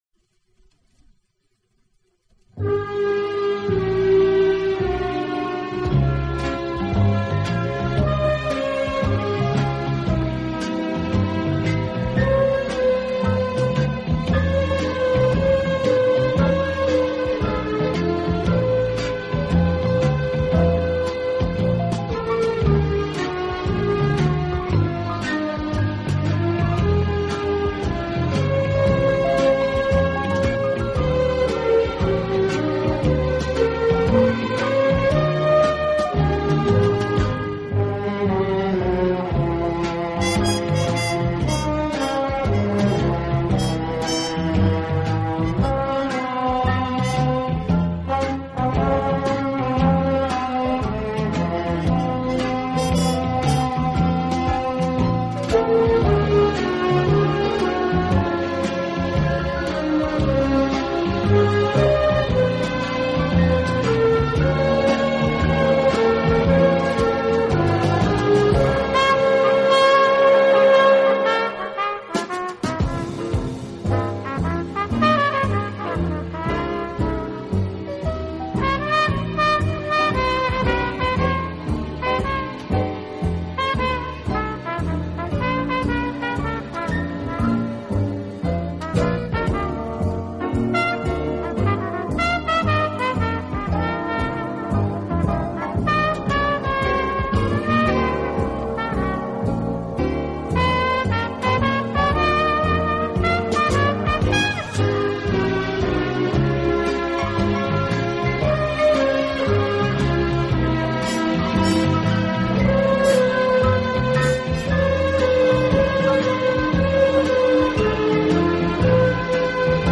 小号的音色，让他演奏主旋律，而由弦乐器予以衬托铺垫，音乐风格迷人柔情，声情并
温情、柔软、浪漫是他的特色，也是他与德国众艺术家不同的地方。